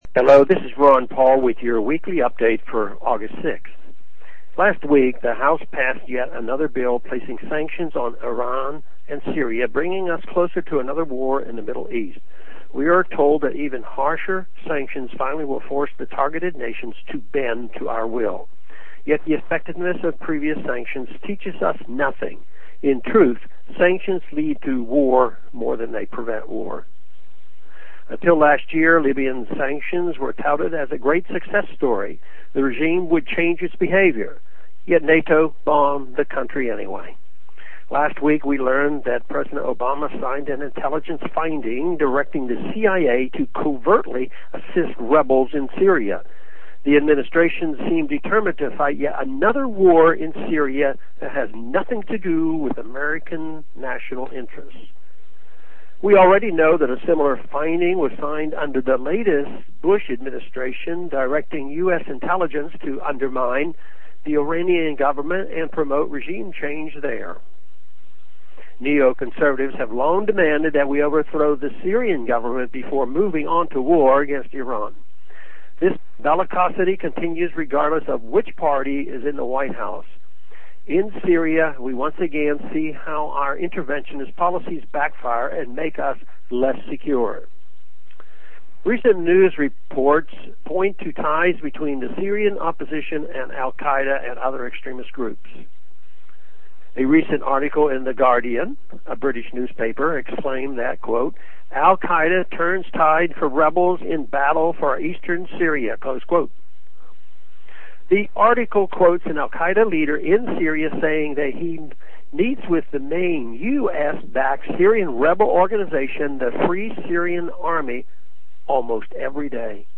Listen to Rep. Paul deliver this address.